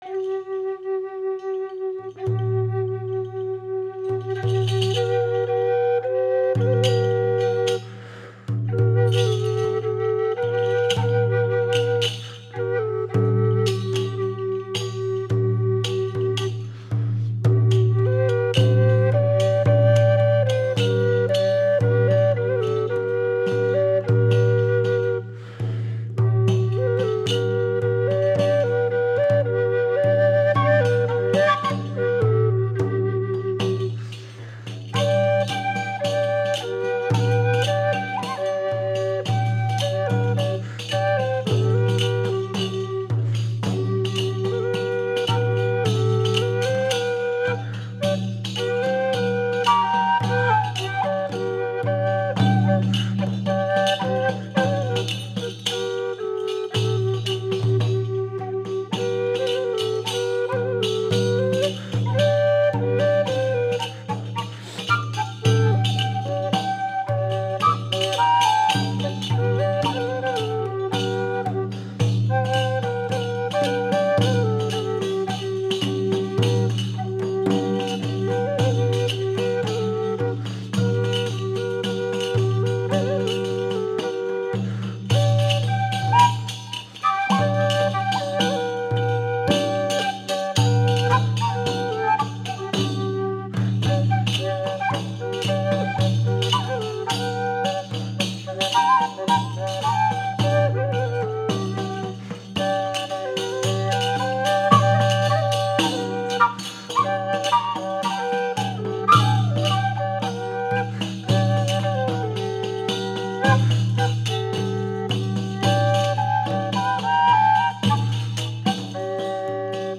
Romeinse Muziek